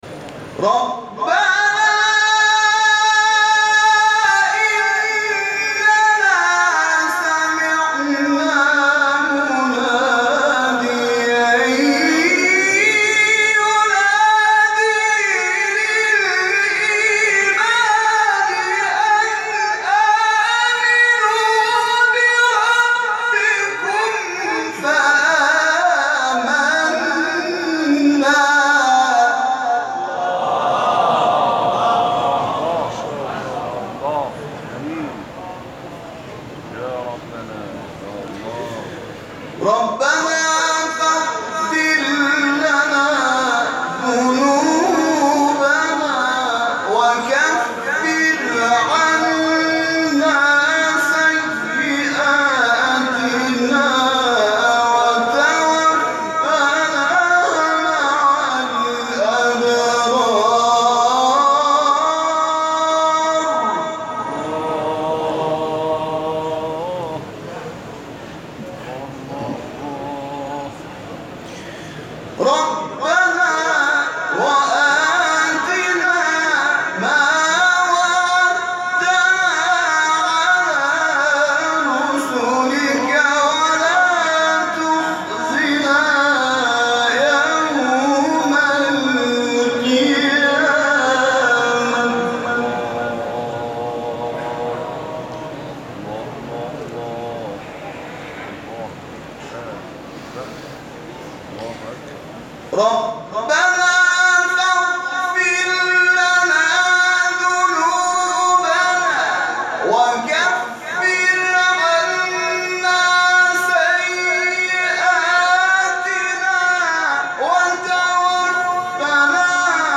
گروه شبکه اجتماعی: فرازهای صوتی از تلاوت قاریان بین‌المللی و ممتاز کشور را که به تازگی در شبکه‌های اجتماعی منتشر شده است، می‌شنوید.
در مقام بیات